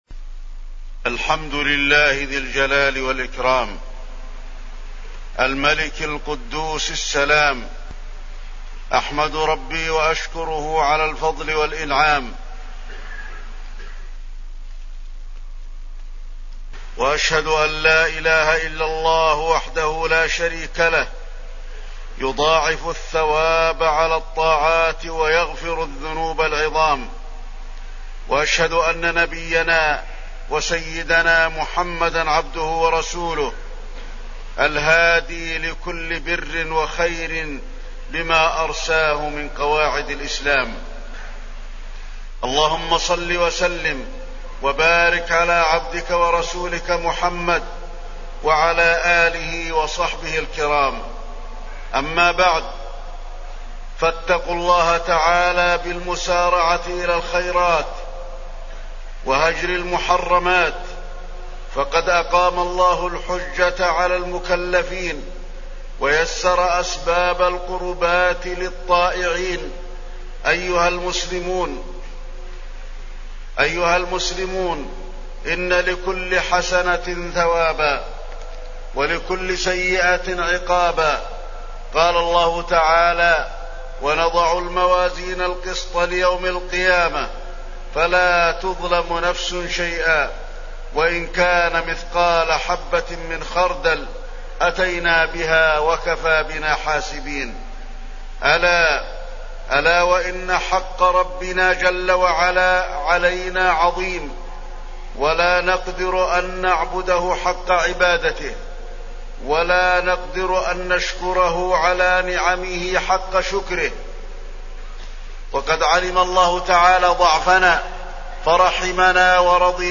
تاريخ النشر ٢٦ رمضان ١٤٢٩ هـ المكان: المسجد النبوي الشيخ: فضيلة الشيخ د. علي بن عبدالرحمن الحذيفي فضيلة الشيخ د. علي بن عبدالرحمن الحذيفي الحسنات والسيئات